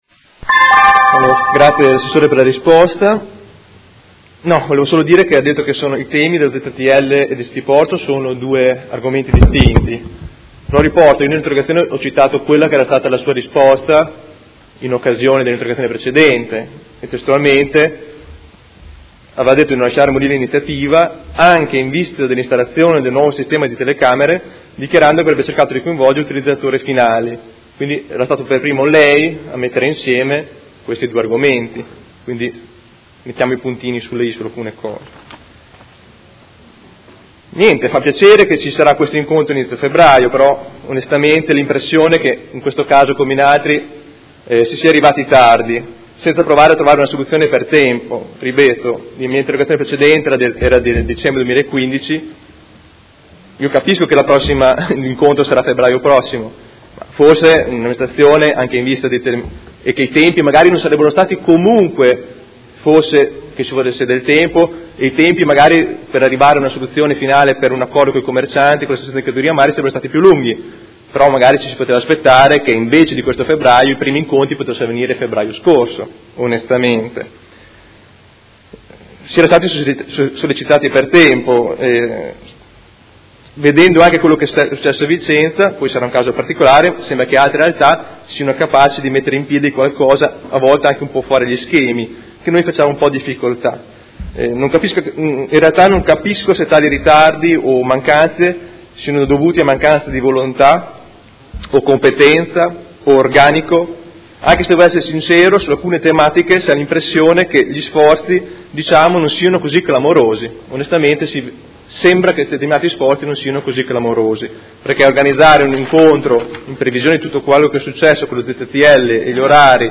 Marco Rabboni — Sito Audio Consiglio Comunale
Seduta del 19/01/2017 Interrogazione del Gruppo Movimento cinque Stelle avente per oggetto: Aggiornamento su progetto Cityporto e ZTL Centro Storico.